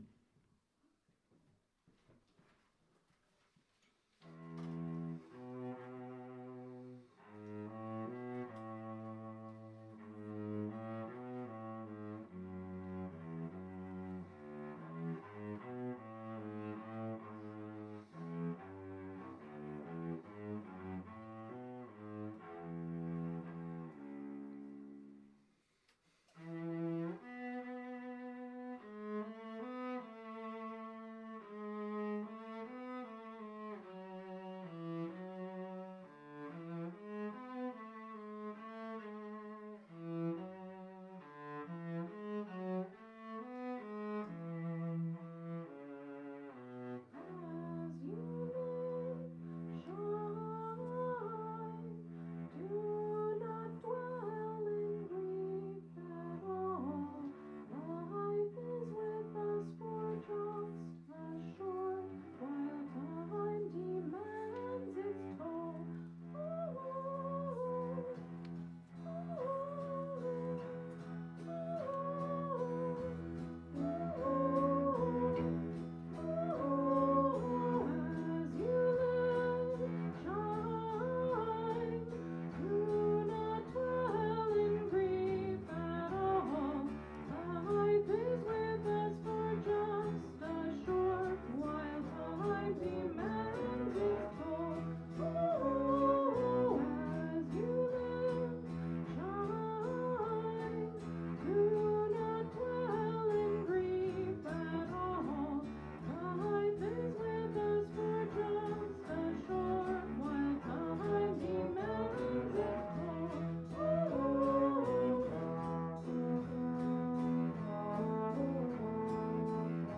For DC Solstice 2024, we introduced this song with: